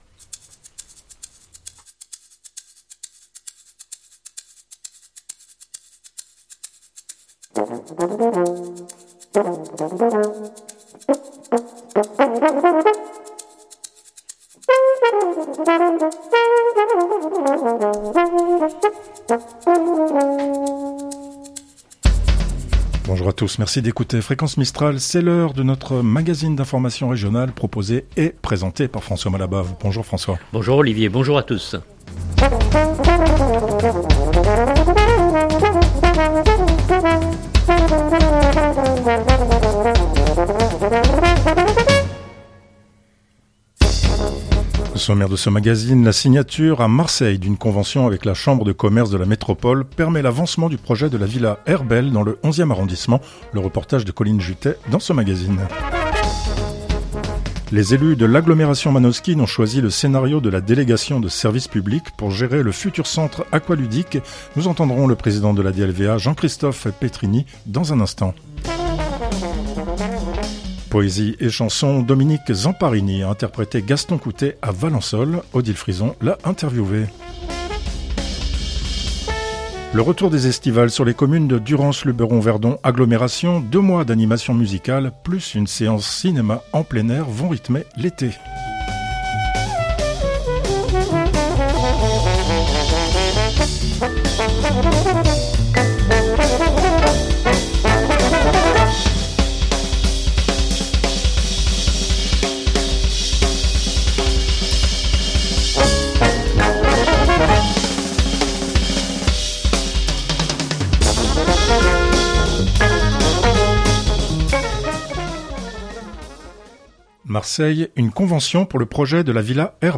un magazine d’information régional
Les élus de l’agglomération manosquine ont choisi le scénario de la délégation de service public pour gérer le futur centre aqualudique. Nous entendrons le président de la DLVA Jean-Christophe Pétrigny dans un instant.